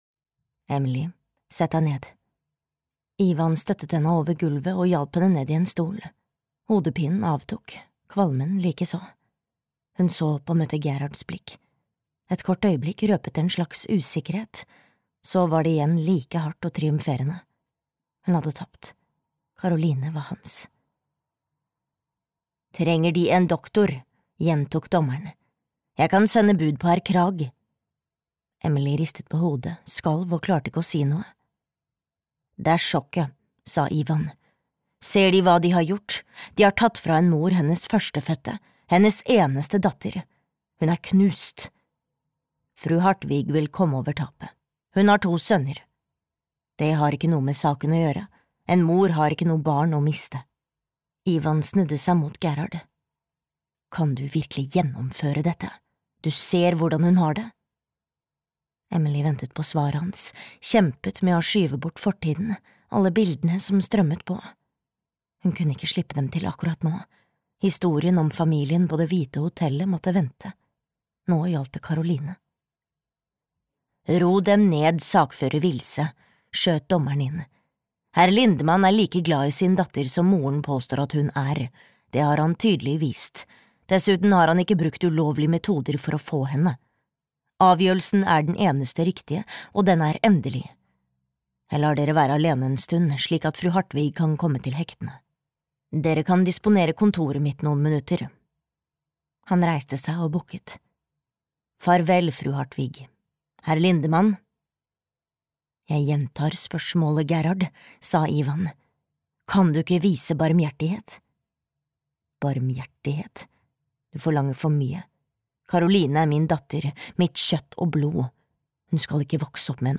Sløret revner (lydbok) av Merete Lien